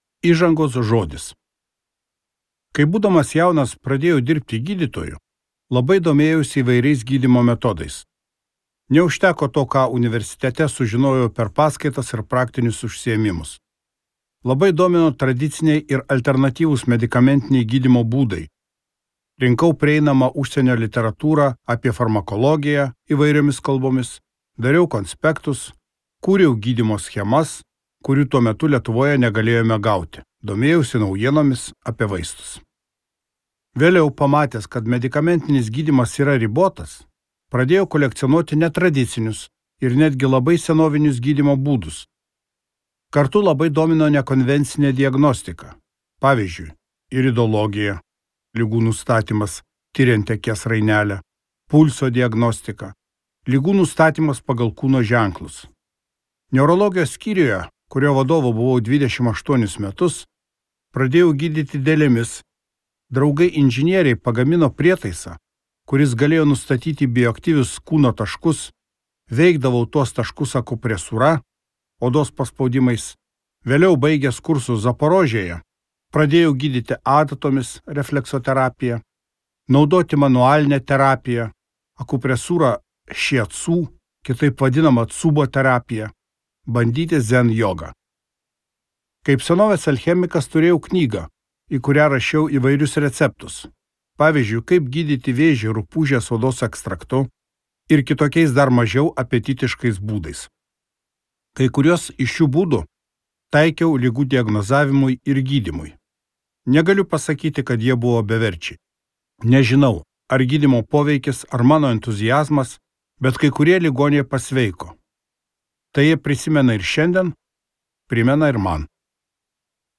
Sveiko ir džiaugsmingo gyvenimo receptai | Audioknygos | baltos lankos